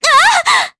Artemia-Vox_Damage_jp_03.wav